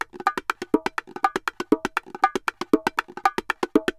Bongo_Salsa 120_2.wav